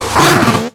Cri de Marcacrin dans Pokémon X et Y.